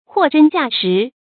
注音：ㄏㄨㄛˋ ㄓㄣ ㄐㄧㄚˋ ㄕㄧˊ
貨真價實的讀法